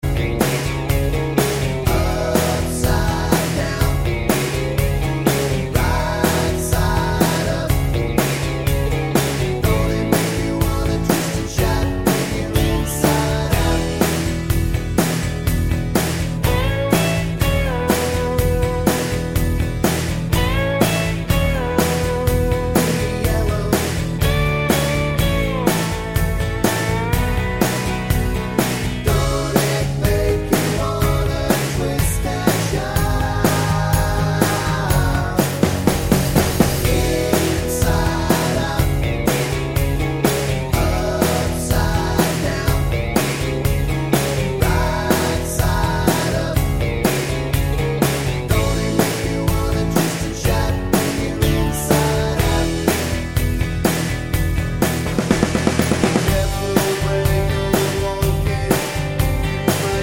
Twofers Medley Pop (1980s) 3:48 Buy £1.50